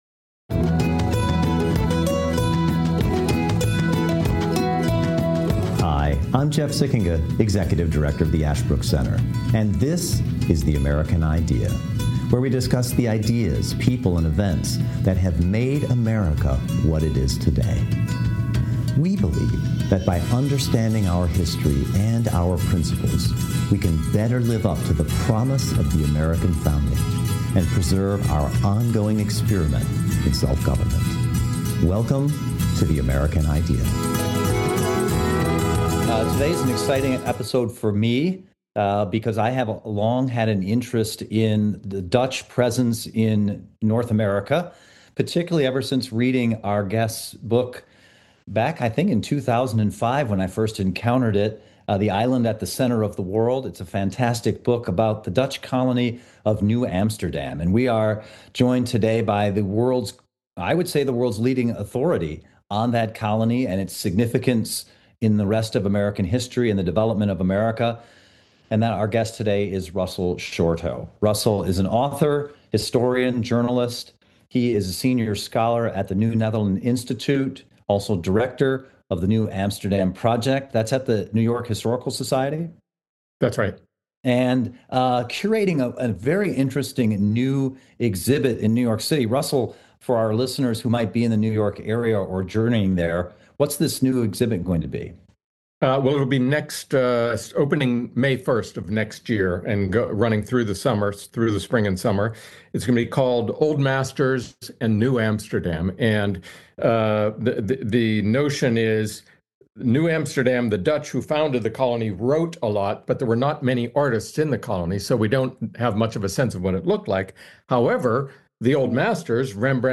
1 Season Three: Episode Six - Interview